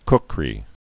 (kkrē)